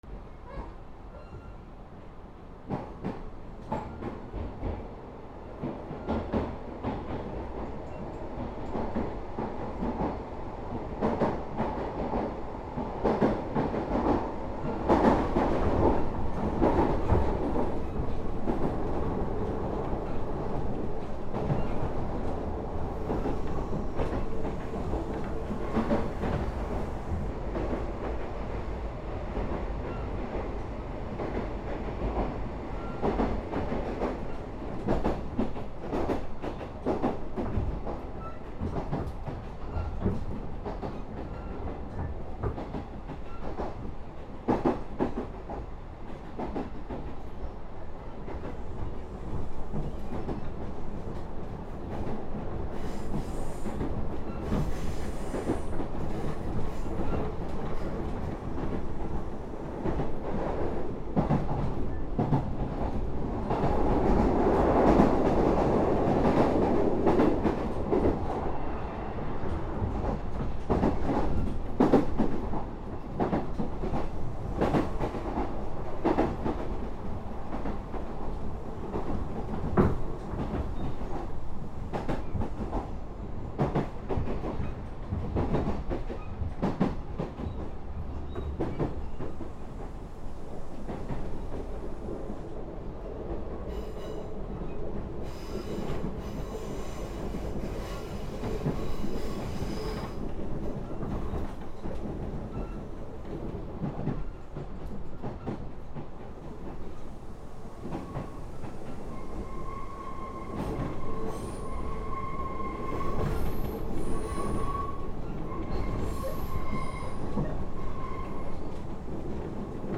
電車発車～走行～停車 車内
/ E｜乗り物 / E-60 ｜電車・駅